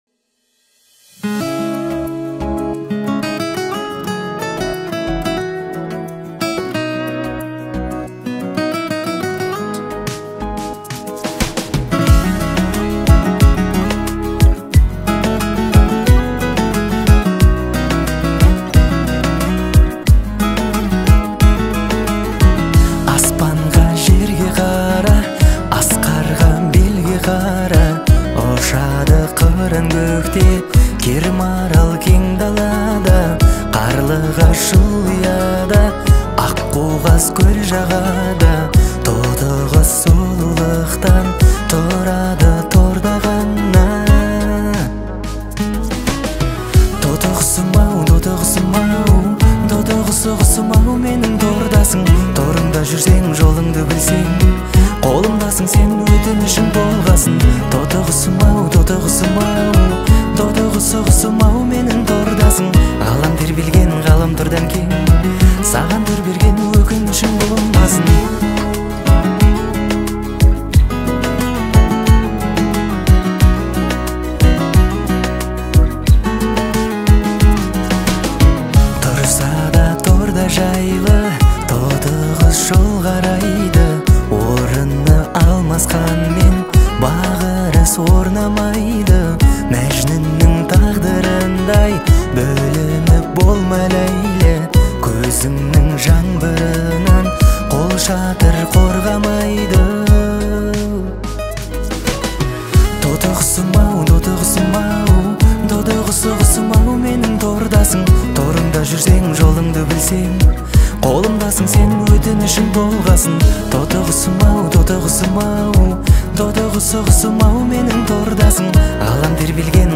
Трек размещён в разделе Поп / Казахская музыка.